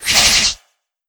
ENEMY_APSARA_STRIKE.ogg